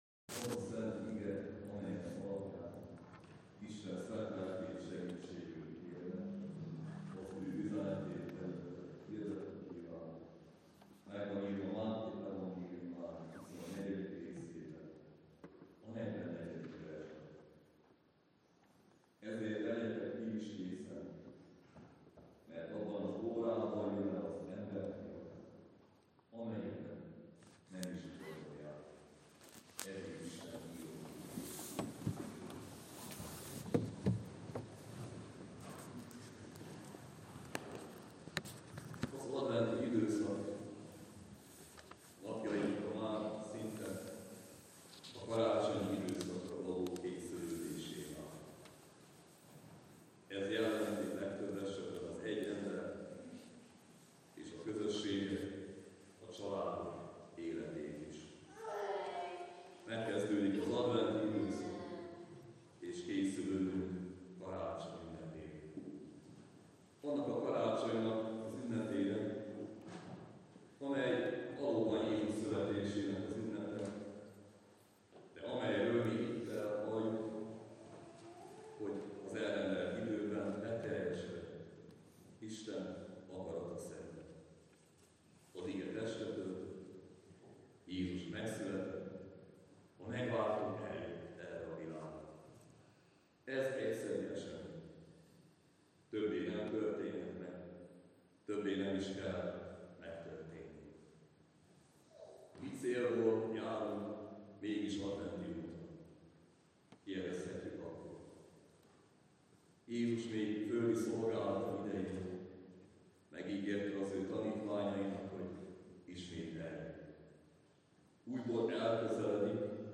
Témakör: Vasárnapi igehirdetések